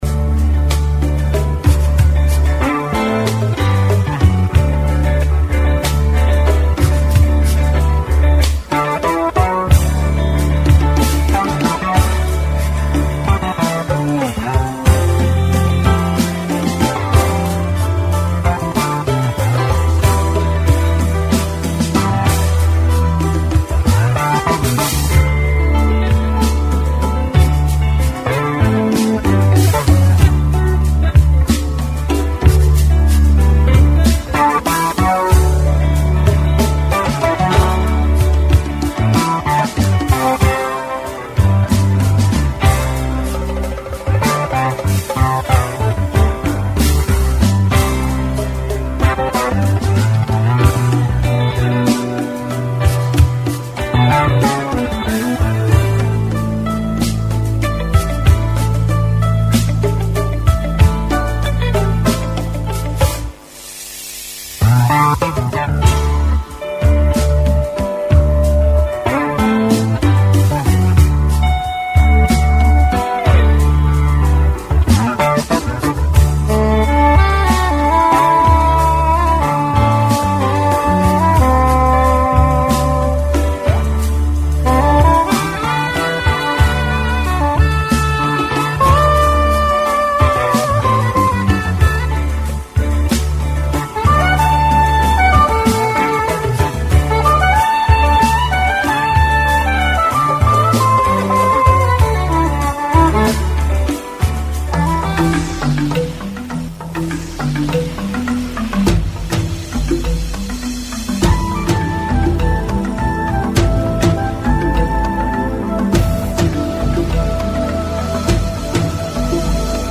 Taarifa ya habari ya tarehe 23 Oktoba 2025